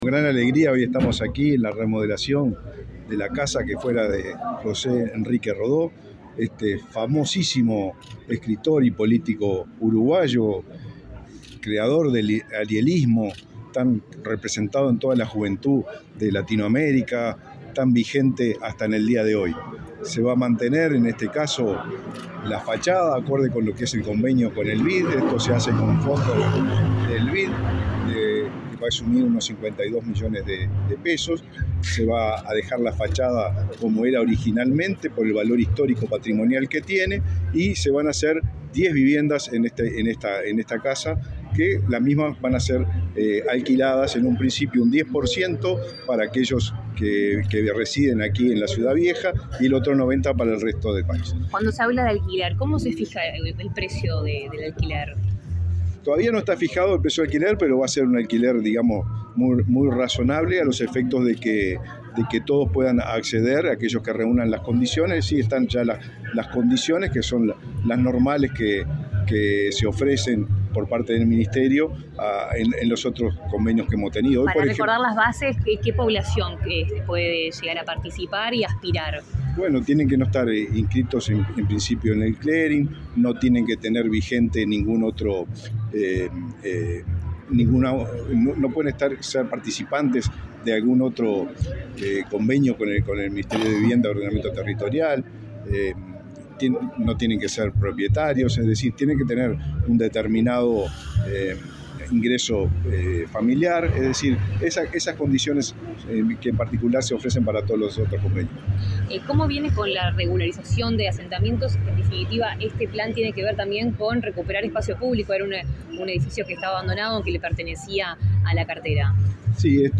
Declaraciones del ministro de Vivienda, Raúl Lozano
Este martes 29, el ministro de Vivienda, Raúl Lozano, dialogó con la prensa, antes de participar en el acto de inicio de obras de diez viviendas en la